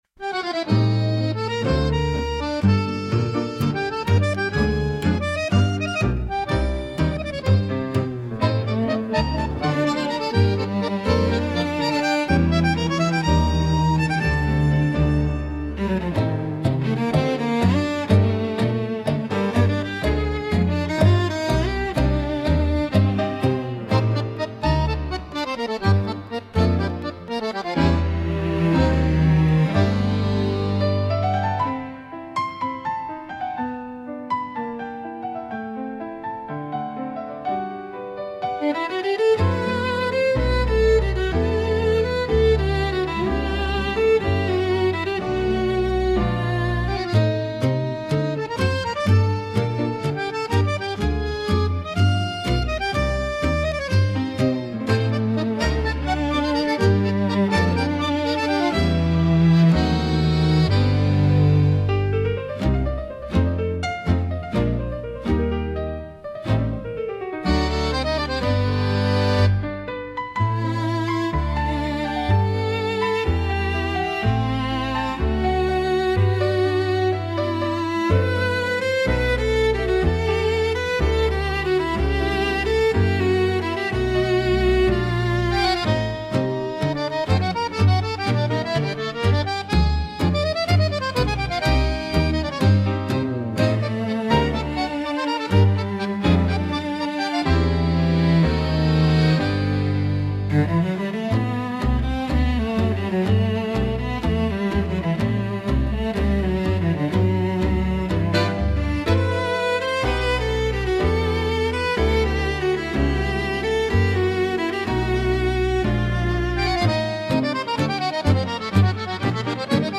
música, arranjo: IA) (instrumental